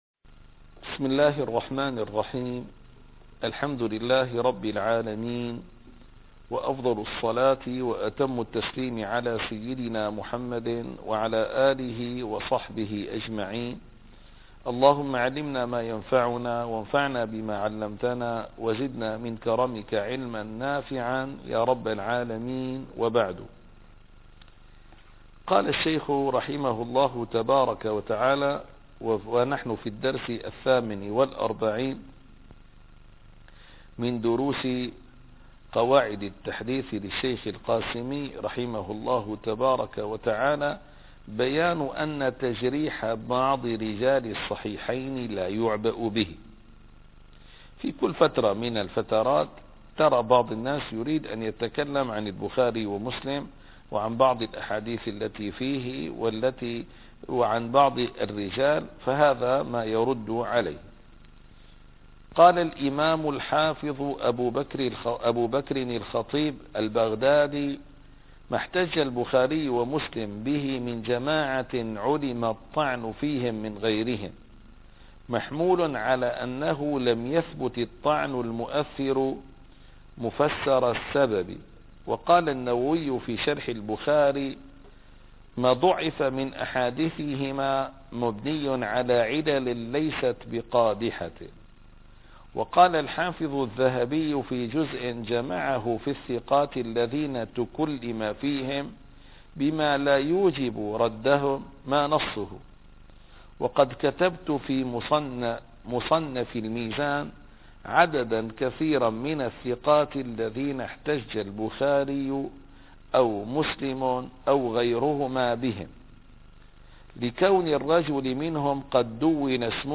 - الدروس العلمية - قواعد التحديث من فنون مصطلح الحديث - 48- بيان أن تجريح ص198